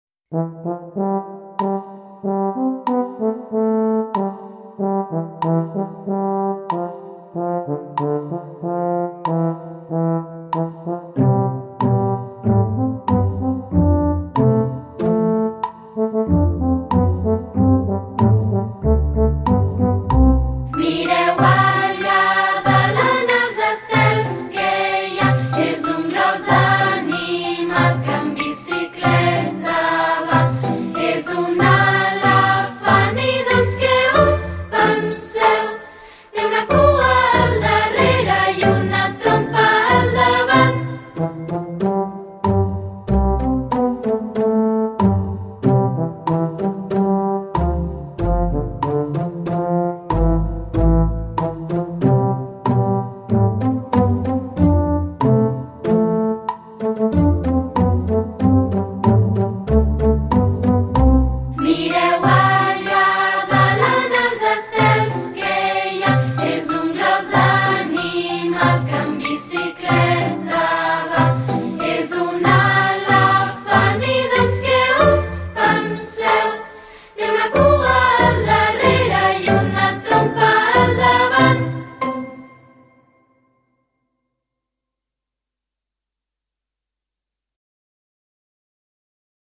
Per fer l’activitat , a més de les imatges del conte de l’Elmer hem necessitat 4 audios ( els sons del lleó, lós i l’elefant i el de la cançó “mireu allà dalt”)